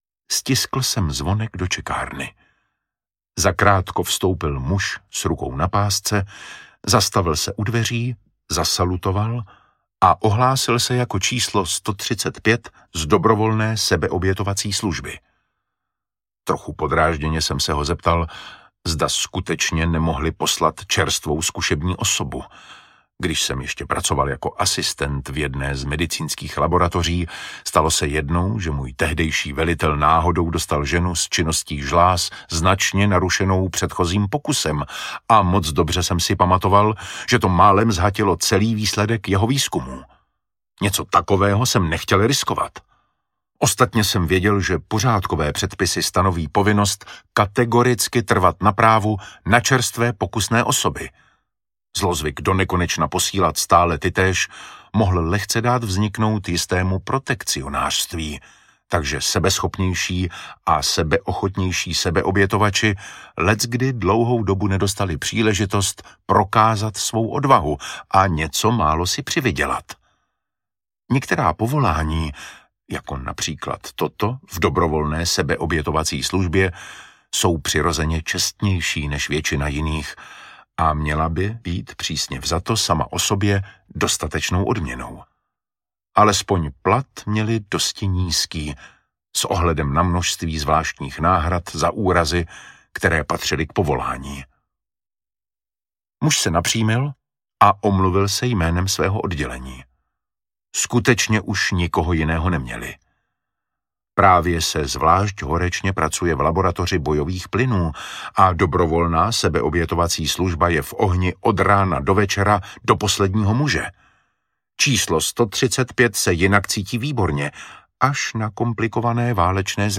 Kallocain audiokniha
Ukázka z knihy
• InterpretDavid Matásek